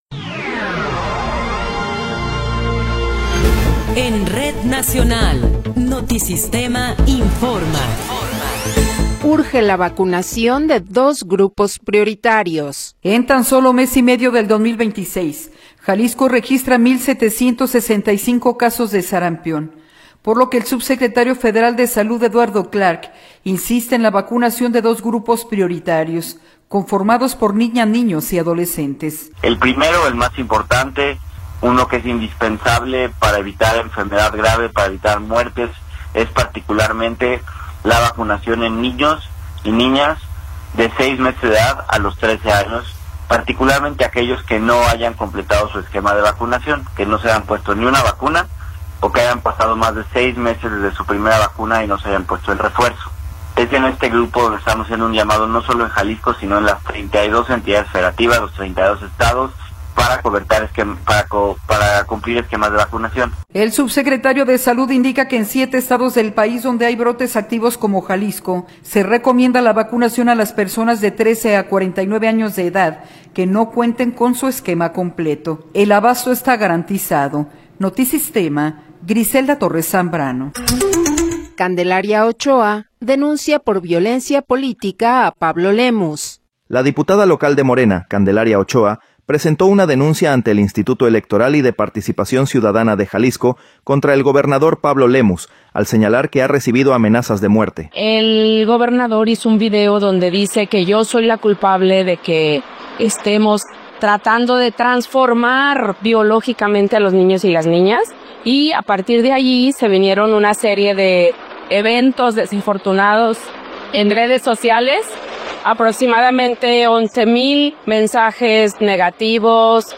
Noticiero 15 hrs. – 16 de Febrero de 2026
Resumen informativo Notisistema, la mejor y más completa información cada hora en la hora.